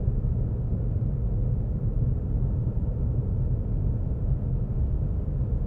background_room_tone_loop_01.wav